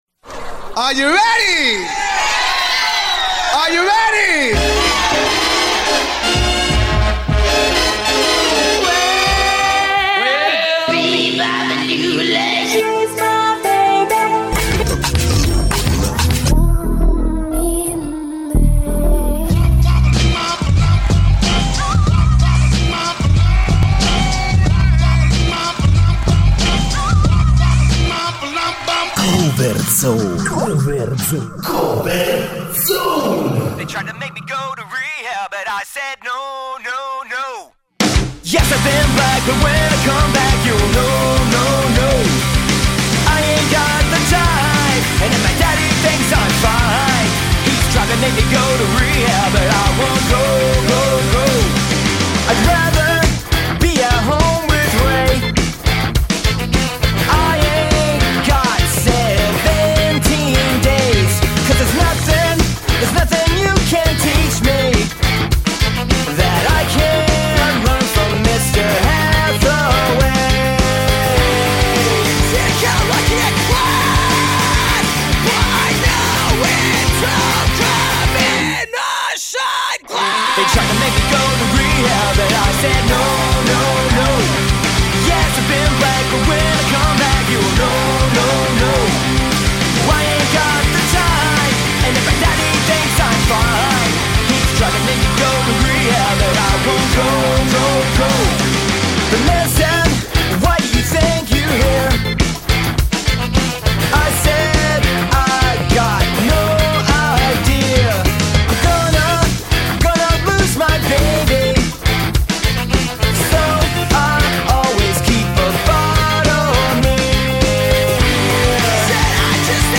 Quelli pronti ad offrire una cumbia surfata e con il risvoltino alla Tarantino!